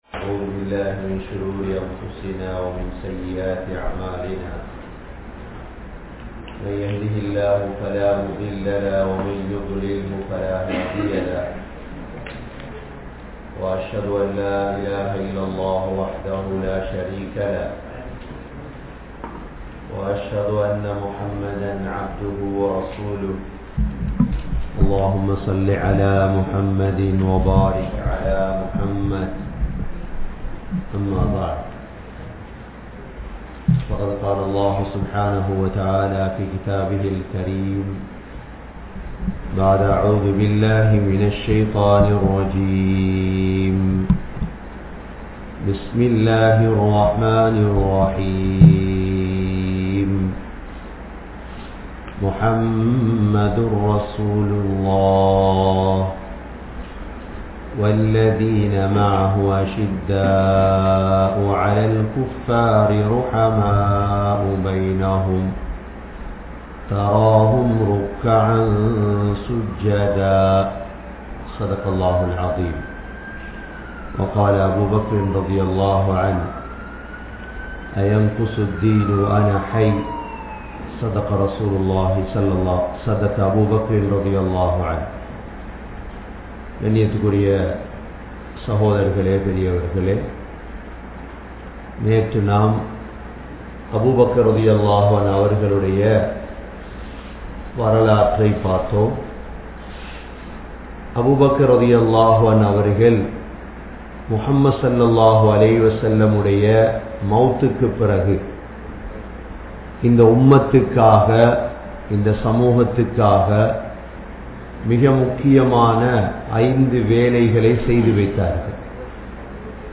Abu Bakr(Rali) (Part 02) | Audio Bayans | All Ceylon Muslim Youth Community | Addalaichenai
Canada, Toronto, Thaqwa Masjidh